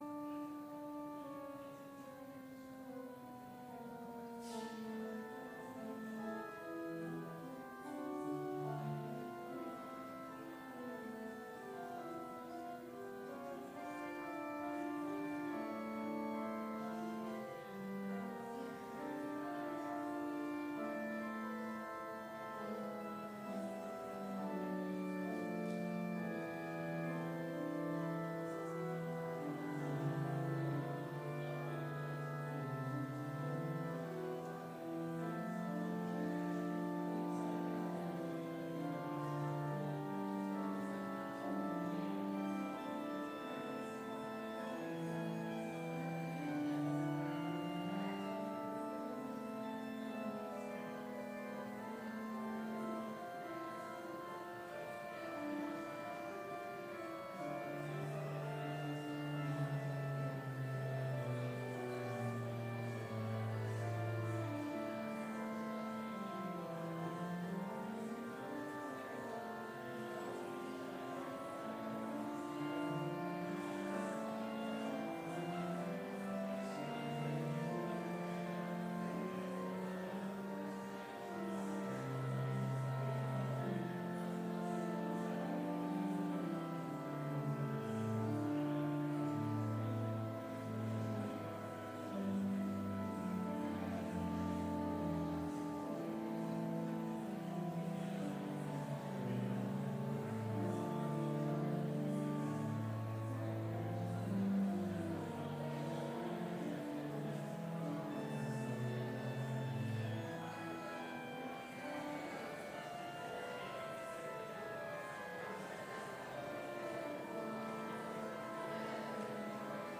Complete service audio for Chapel - September 3, 2019